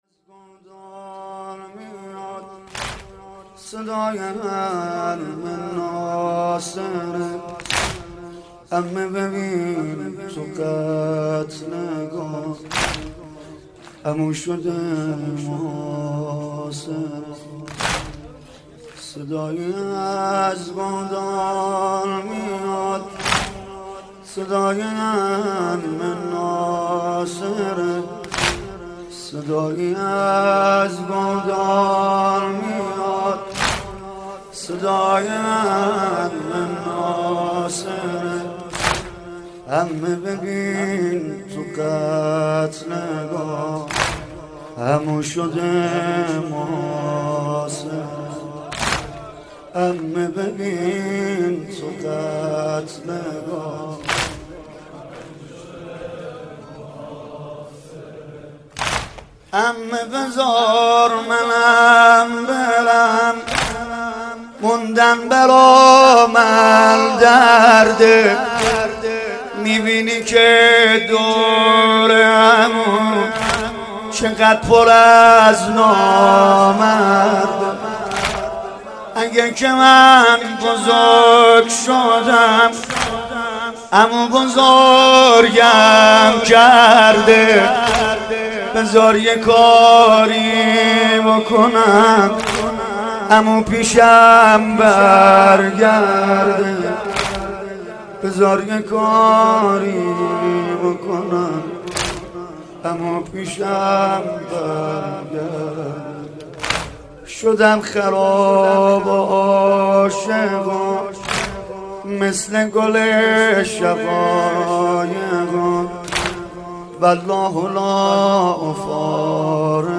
مناسبت : شب پنجم محرم
مداح : سید رضا نریمانی قالب : واحد